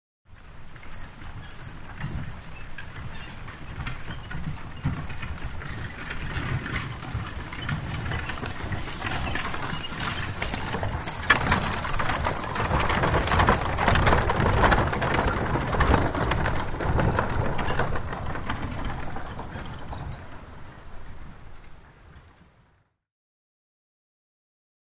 Horse Drawn Wagon; By Slow, Grass Surface, L-r, With Light Background Wind Through Trees